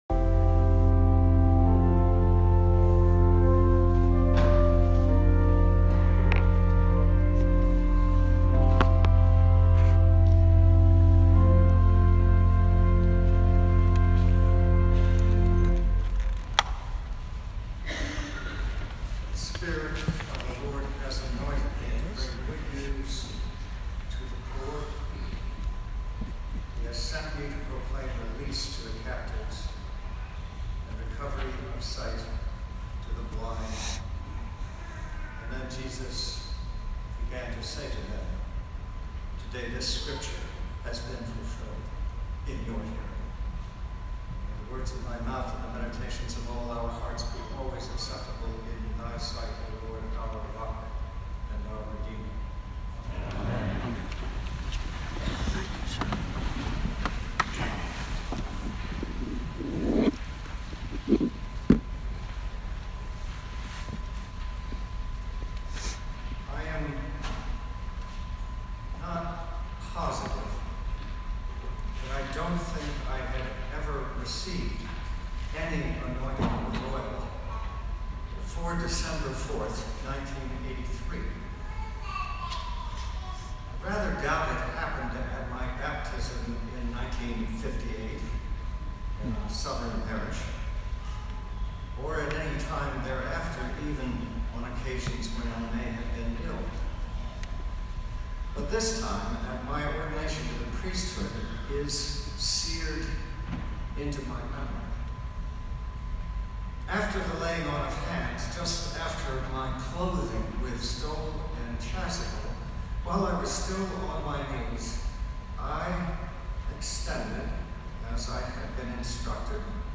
Bp. Dorsey’s Sermon – Renewal of Ordination Vows – 2017
Every Holy Week, Episcopal clergy typical renew their vows from when they were ordained. In the Diocese of Pittsburgh, we share that service with our fellow clergy in the Evangelical Lutheran Church in America. This year, the Lutherans hosted and their bishop presided, while Bp. Dorsey McConnell preached. He preached on the texts from Isaiah 61, Revelation 1, and Luke 4.